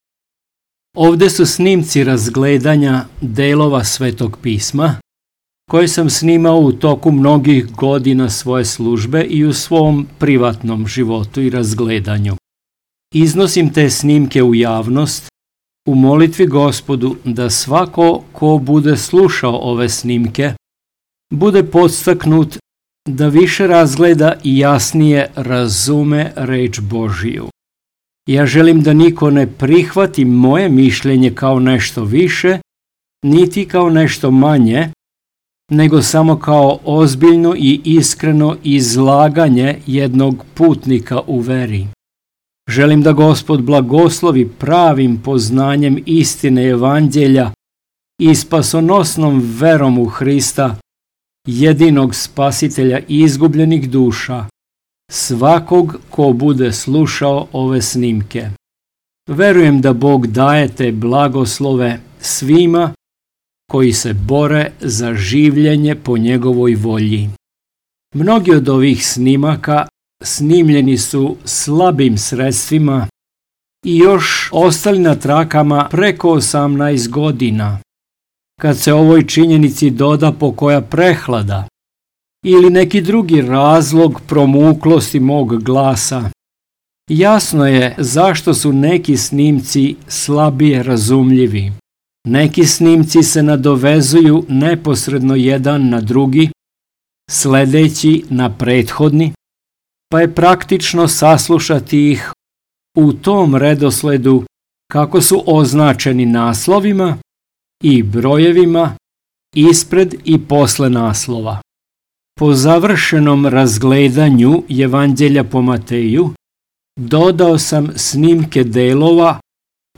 Audio poruka